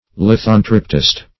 Lithontriptist \Lith"on*trip"tist\, n.